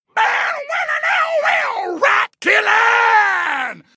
mouth-guitar_03